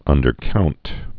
(ŭndər-kount)